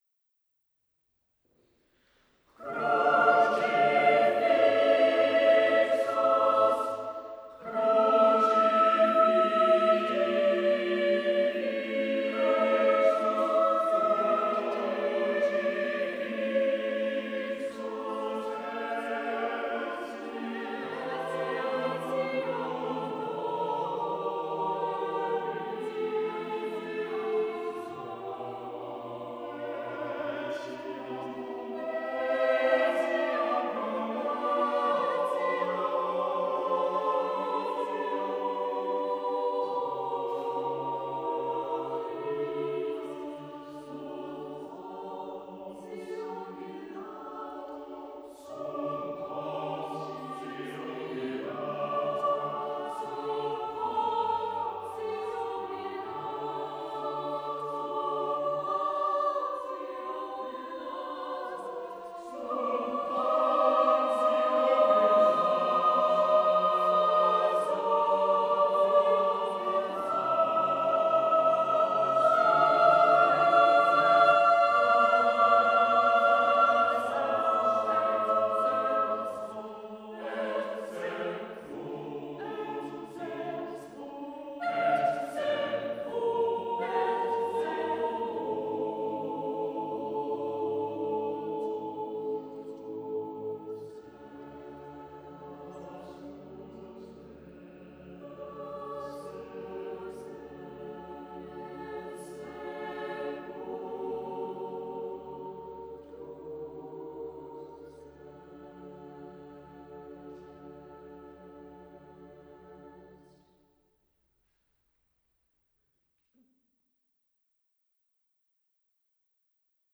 The Cherwell Singers here sing his 6-part, 8-part and 10-part settings in turn. This is from a concert given in Merton College, Oxford, on 24th March 2007.
Ambisonic
Ambisonic order: H (3 ch) 1st order horizontal
Recording device: MOTU Traveler
Microphone name: Home made
Array type: Native B-Format
Capsule type: AKG Blue Line